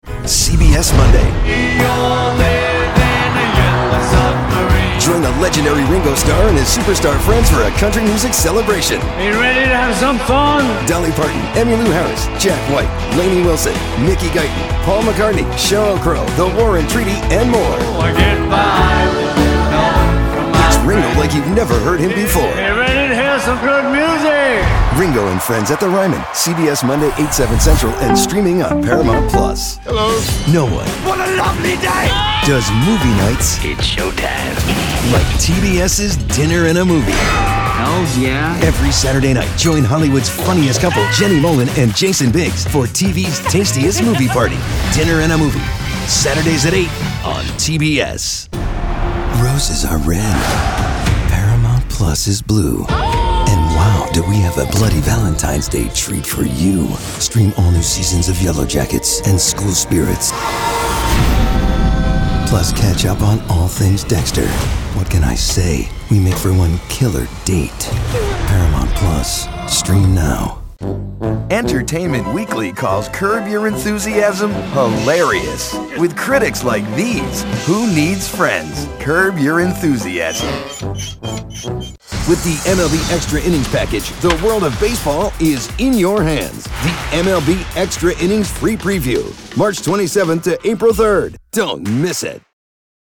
VO / Promo
NEWS PROMO DEMO